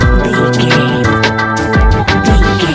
Ionian/Major
D♭
Lounge
sparse
new age
chilled electronica
ambient
atmospheric